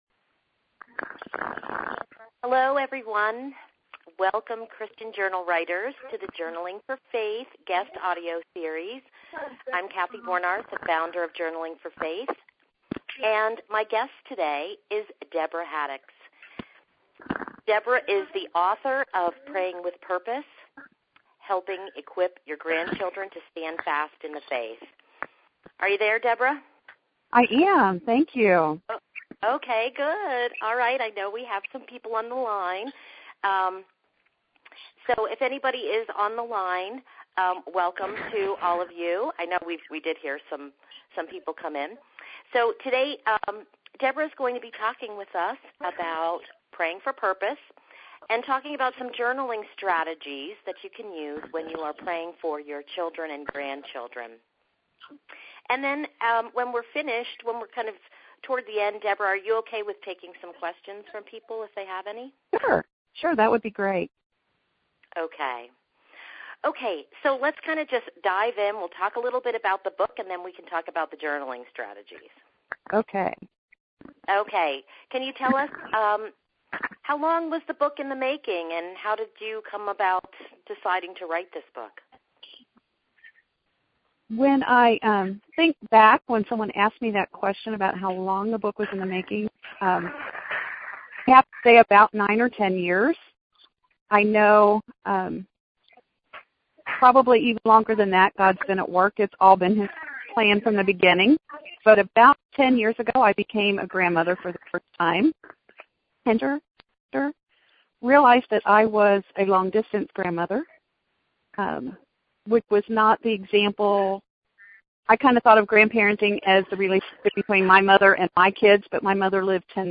An audio interview